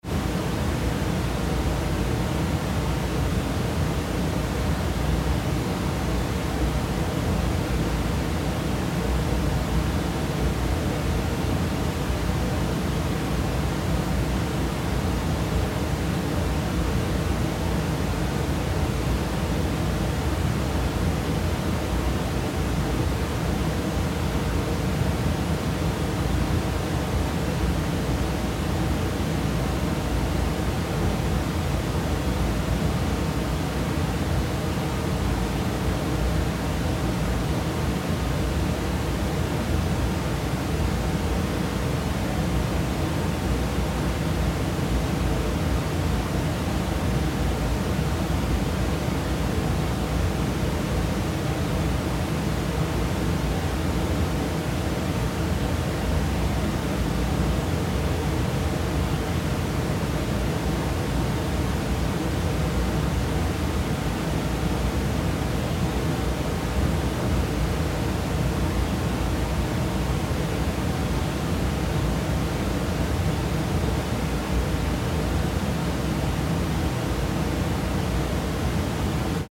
2 Lautsprecher-Kanäle, Stühle
"komplementäres Rauschen",
Lautsprecher-Installationen und Stücke auf CD
2 Lautsprecher, Stühle (1995, Neufassung 2001)